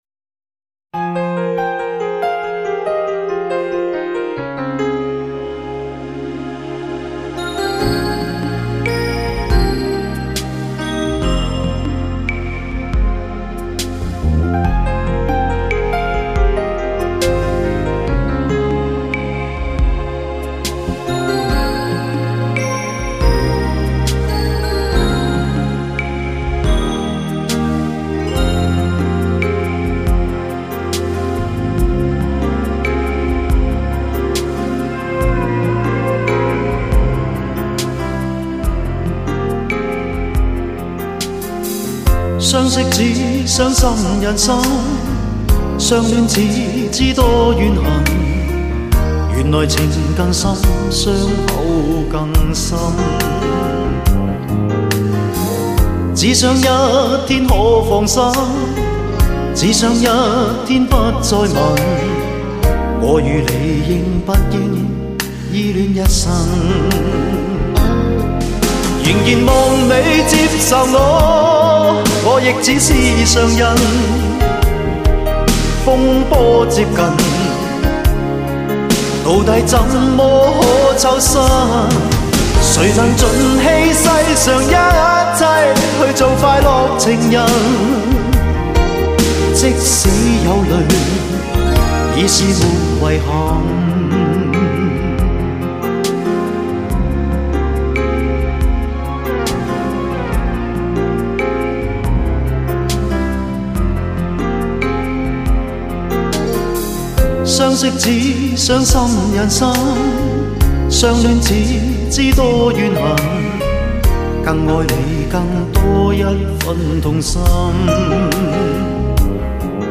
唱片类型：流行经典
材专业监听精品CD，现今高端压片科技下音效最HI-FI的产物。